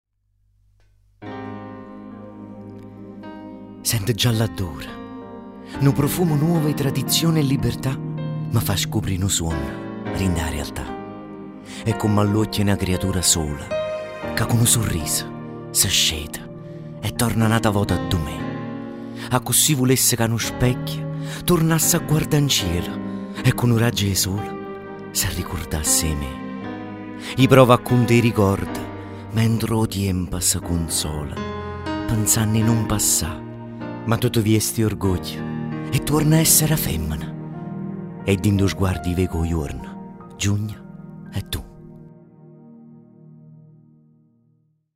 Poesia